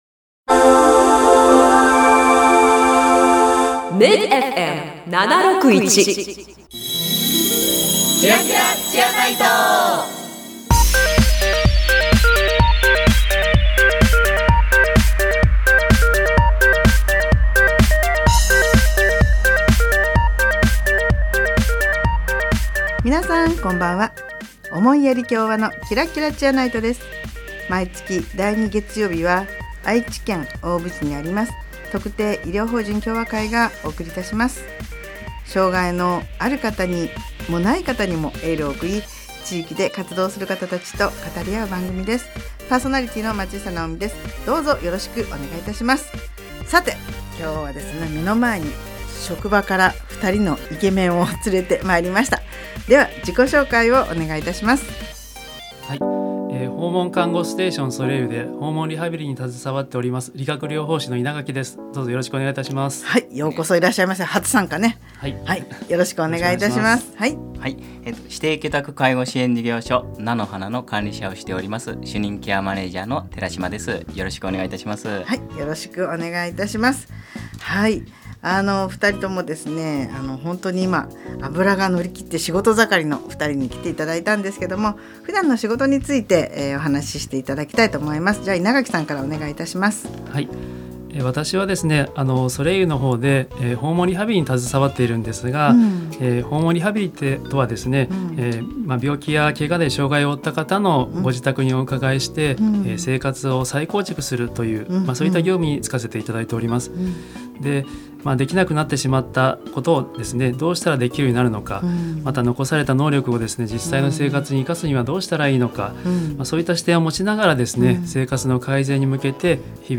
【放送時間 】第2月曜日 19：00 MID-FM 76.1 【ゲスト】
この番組では、地域の医療・福祉に携わる方々と語り合い、偏見にさらされやすい障がいのある方に心からのエールを送ります。 毎回、医療・福祉の現場に直接携わる方などをゲストに迎え、現場での色々な取り組みや将来の夢なども語り合います。